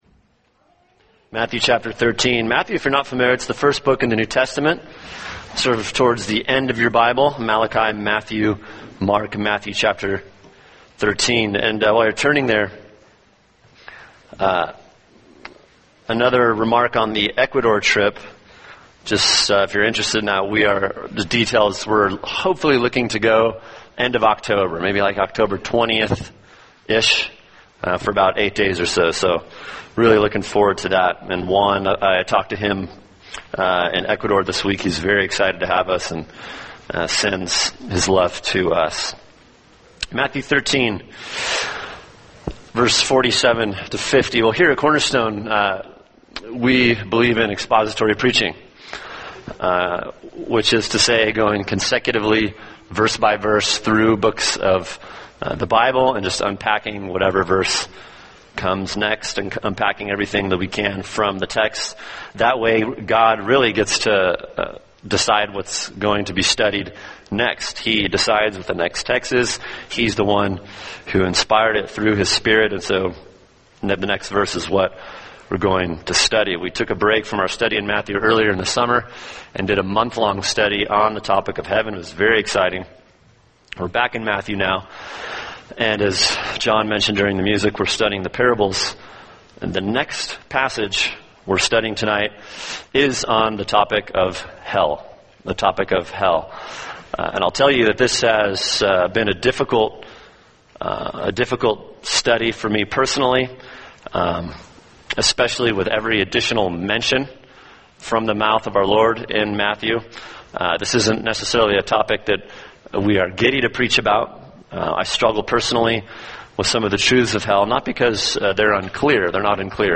[sermon] Matthew 13:47-50 – The Truth About Hell | Cornerstone Church - Jackson Hole